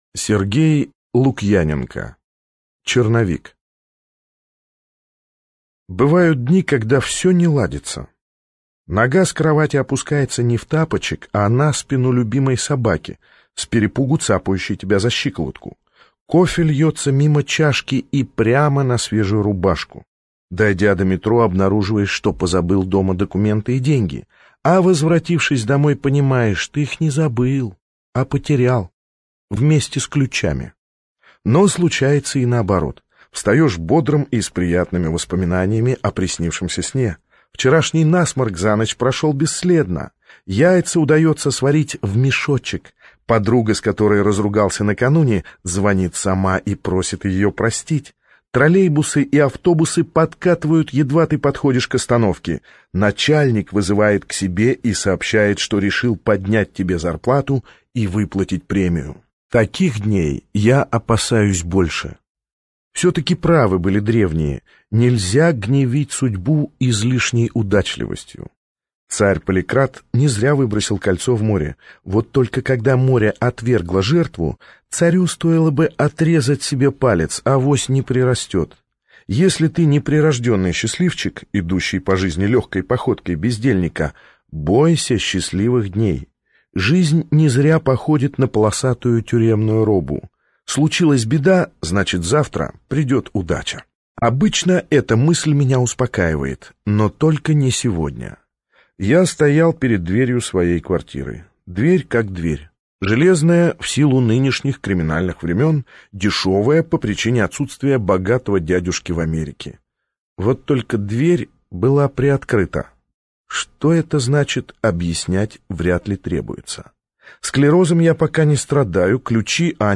Аудиокнига Черновик - купить, скачать и слушать онлайн | КнигоПоиск
Аудиокнига «Черновик» в интернет-магазине КнигоПоиск ✅ в аудиоформате ✅ Скачать Черновик в mp3 или слушать онлайн